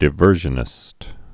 (dĭ-vûrzhə-nĭst, -shə-, dī-)